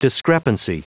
Prononciation du mot discrepancy en anglais (fichier audio)
Prononciation du mot : discrepancy